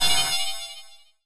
sci-fi_cockpit_computer_problem_01.wav